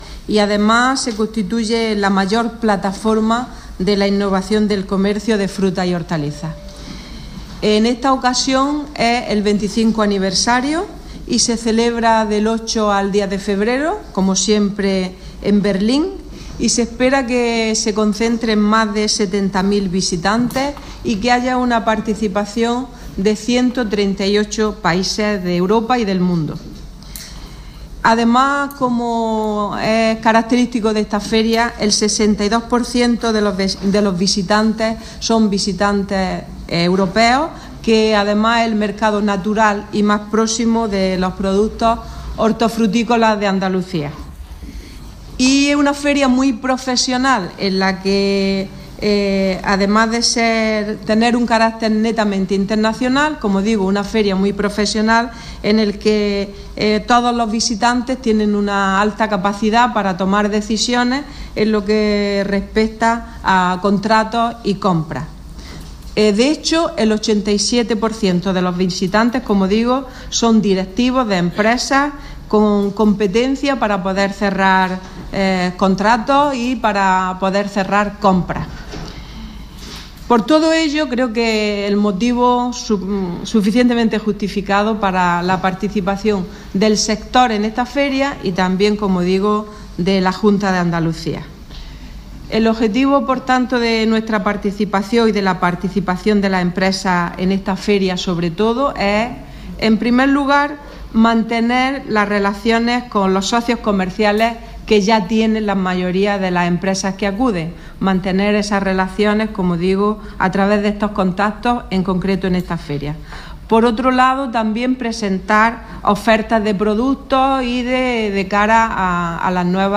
Declaraciones de Carmen Ortiz sobre Fruit Logistica 2017